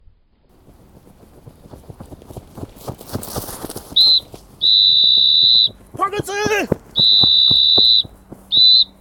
Zeltlager-Wecker.mp3